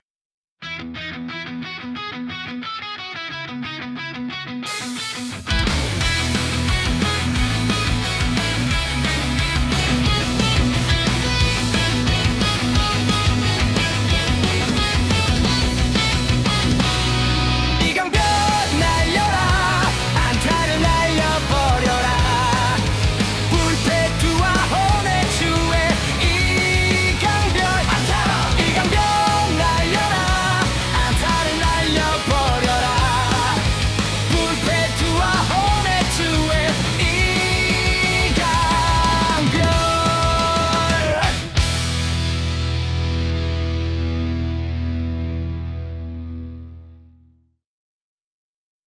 응원가 구단 자작곡 (2013~)[1]